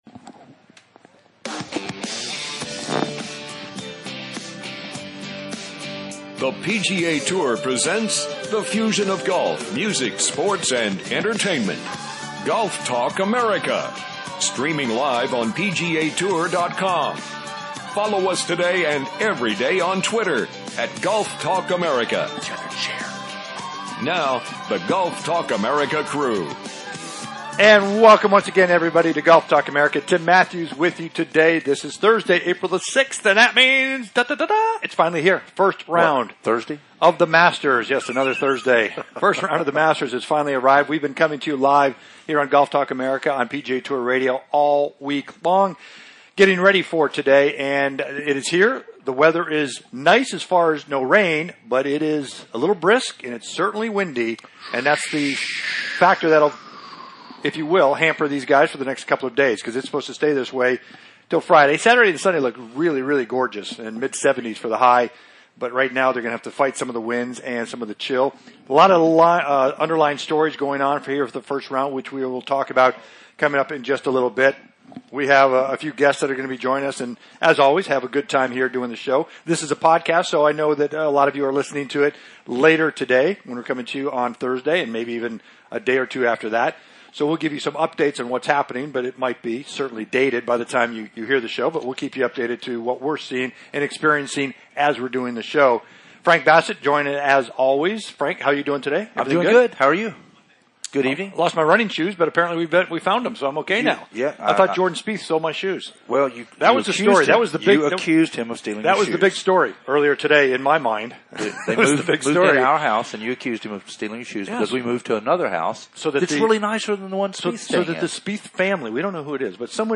Day One from THE MASTERS...LIVE!